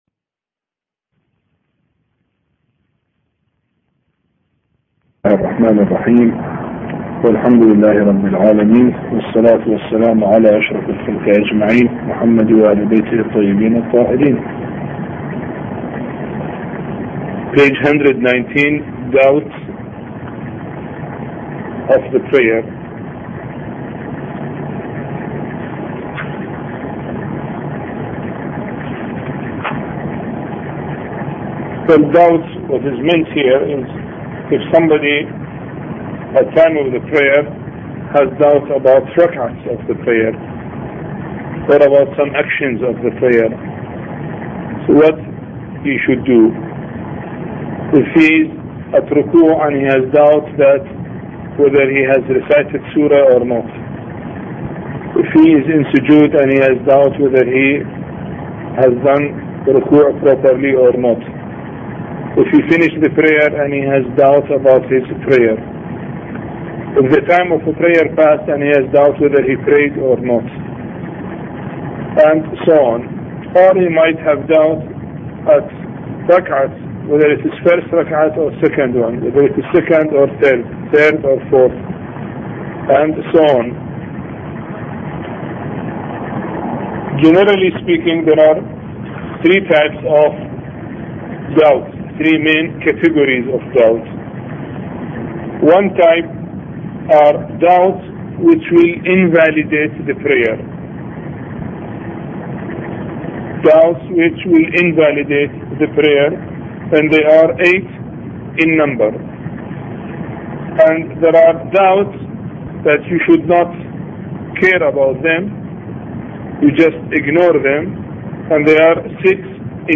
A Course on Fiqh Lecture 20